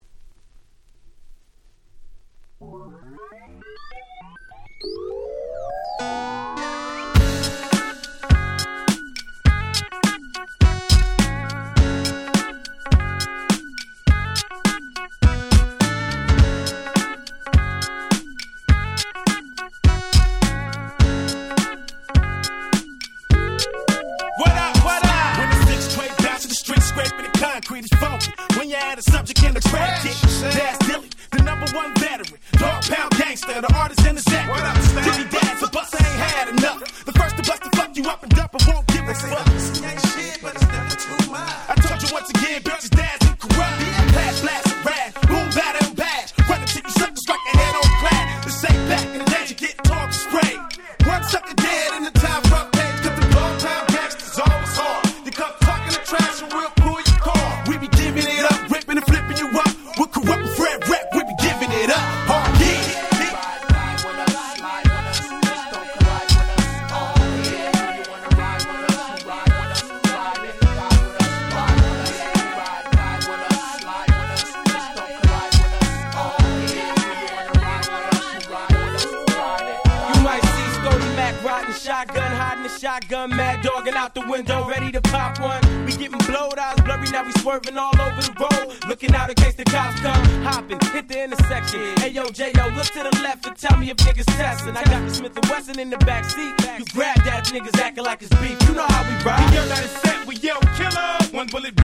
01' Smash Hit West Coast Hip Hop !!